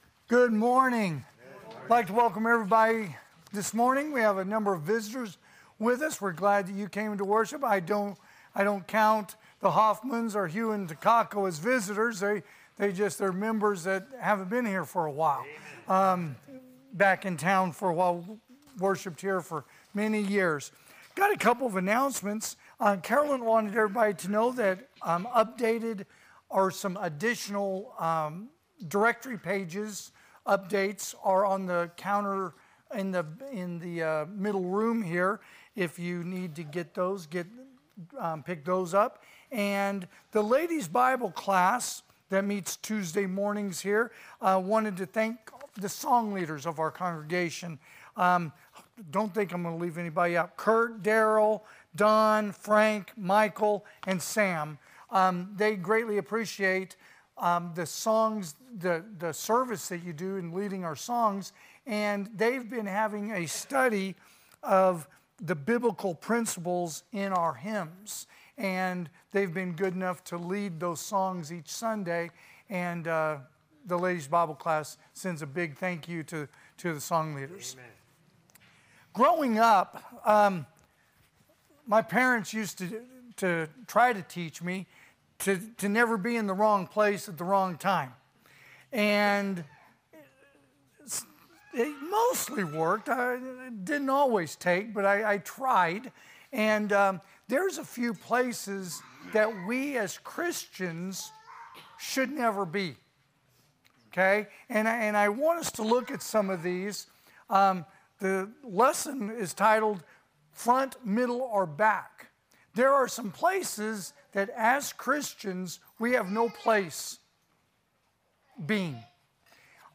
2024 (AM Worship) "Front